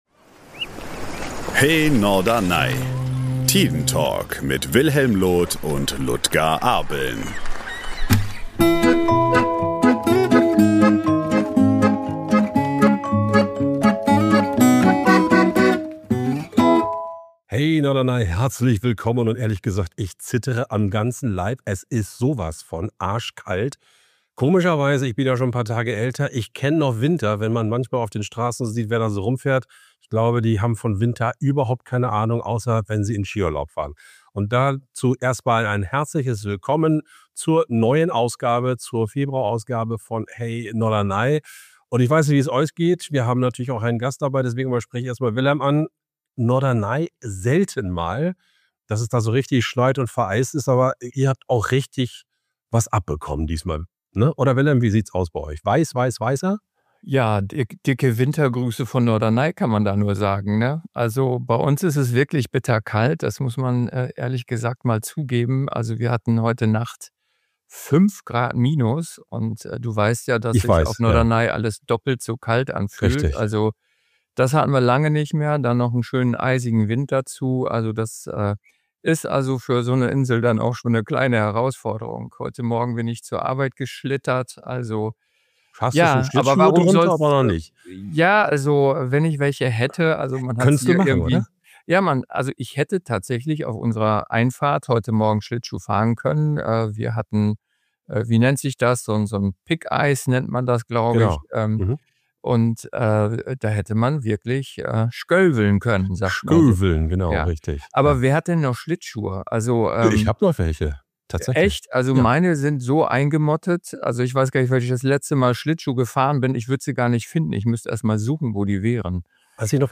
Die drei sprechen aber auch über den kuriosen Gegensatz: Während manche Kölner*innen das närrische Treiben gar nicht abwarten können, suchen andere in dieser Zeit gezielt Ruhe – und finden sie auf Norderney. Die Insel wird damit zur Rückzugsoase für sogenannte „Karnevalsflüchtlinge“.